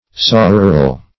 Sororal \So*ro"ral\, a. [L. soror sister: cf. F. sororal.]